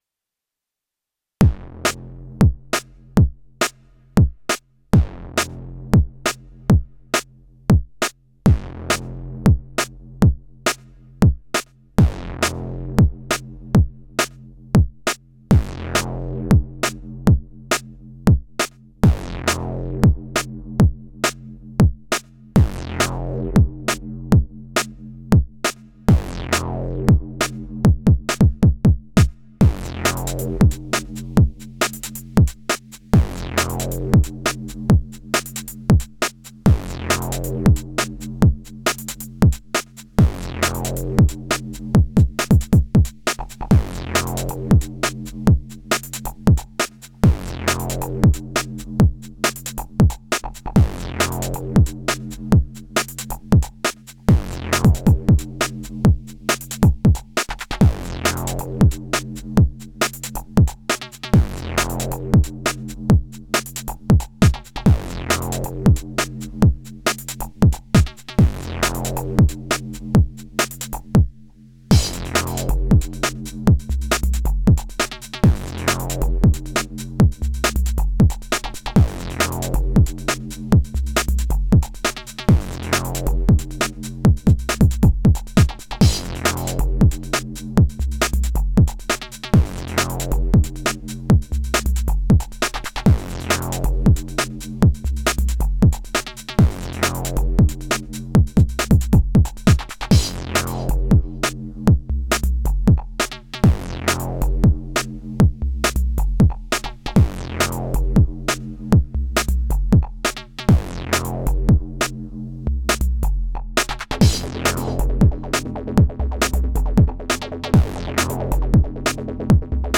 I did record this a few weeks ago, I tried out an exercise from the Ableton “Creative Strategies” book and listened carefully to the track “Stress Test” by Stenny, took notes, then made a track just based on the notes, using just internal AR sounds and resampling…
I thought it sounded good at the time but when I listen on my monitors the kick sounds a bit off – I don’t really know what I am doing with the compressor yet lol.
Very nice buildup!